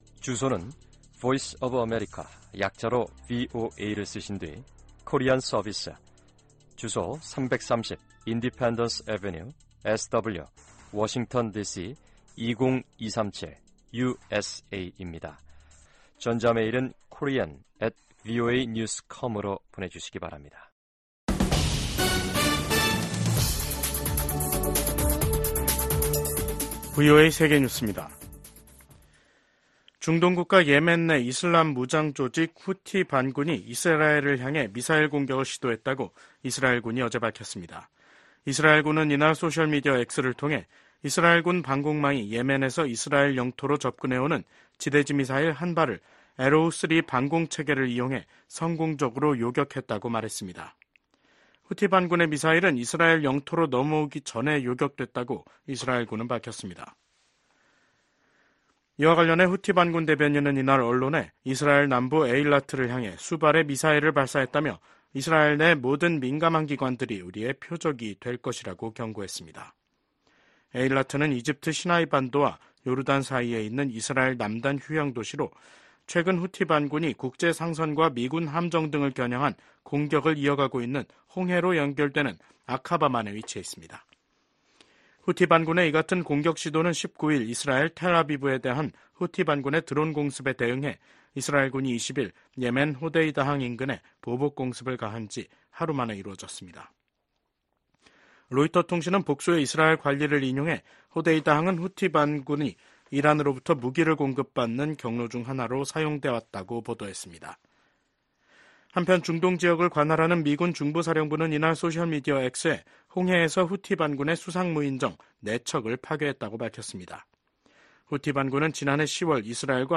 VOA 한국어 간판 뉴스 프로그램 '뉴스 투데이', 2024년 7월 22일 2부 방송입니다. 도널드 트럼프 전 대통령이 공화당 대선 후보 수락 연설에서 미국 사회의 불화와 분열이 빠르게 치유돼야 한다고 강조했습니다. 미한일 합참의장이 3국 다영역 훈련인 프리덤 에지 훈련을 확대하기로 합의했습니다. 북한 군인들이 국제법에 반하는 심각한 강제노동에 시달리고 있다는 우려가 국제사회에서 제기되고 있습니다.